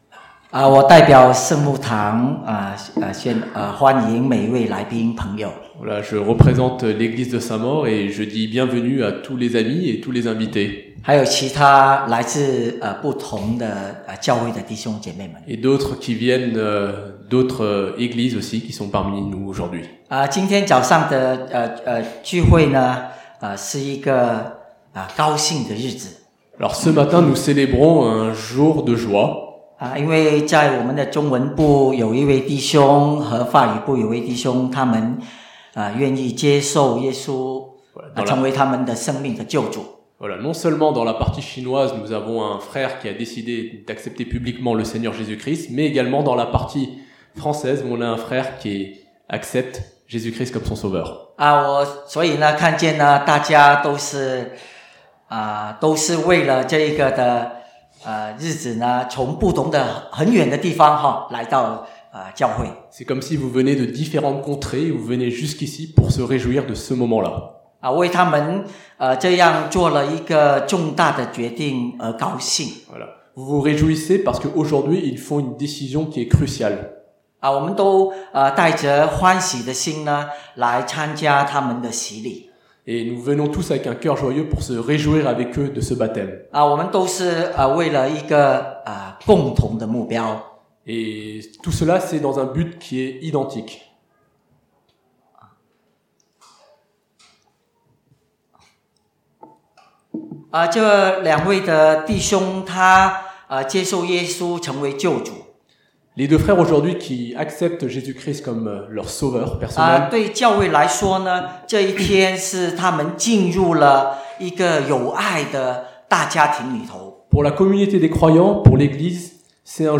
(chinois traduit en français)
Dieu rend capable l’homme d’aimer comme lui aime. 3 choses en destination des futurs baptisés aujourd’hui : (il s’agit d’un culte de baptême) 1.